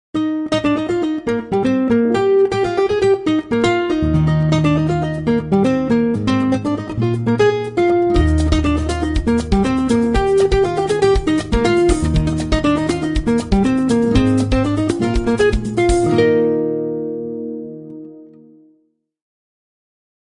Sound Effects
electric , 80s , 80 , 808 , cowbell ,